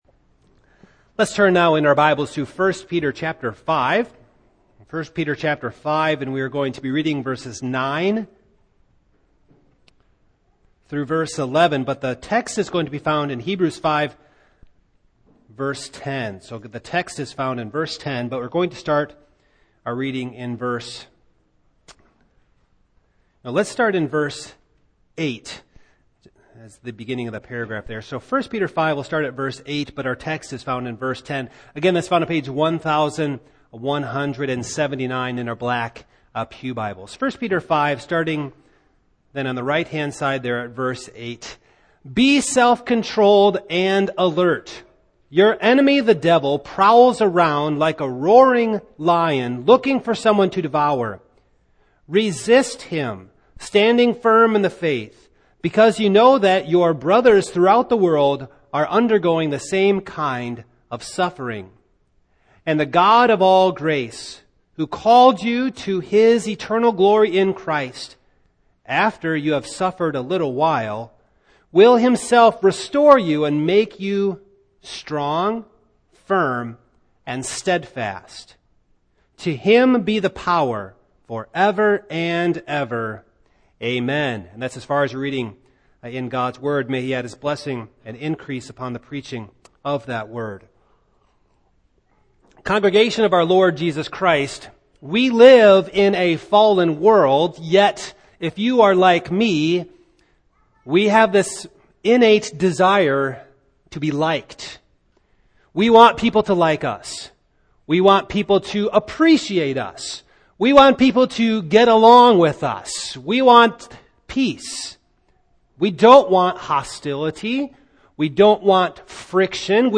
Series: Single Sermons
Service Type: Evening